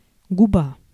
Ääntäminen
IPA: [lɛvʁ]